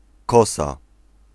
Ääntäminen
Ääntäminen Tuntematon aksentti: IPA: /ˈkɔsa/ Haettu sana löytyi näillä lähdekielillä: puola Käännös Substantiivit 1. viikate Suku: f .